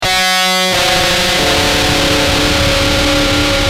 Screamb5.wav